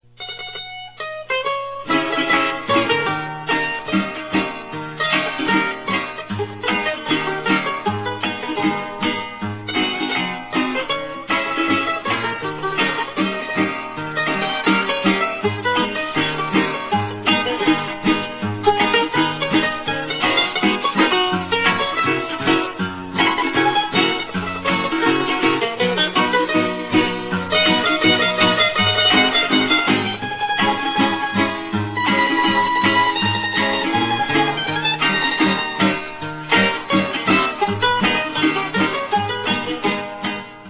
BANDOLIN O MANDOLINA
Grabación: Canto de fulía
Ensamble: Cuatro, guitarra, maracas
Característica: Género musical propio de la región oriental de Venezuela
Procedencia, año: Cumaná, Estado Sucre, Venezuela, 1973
bandolin_o_mandolina.ra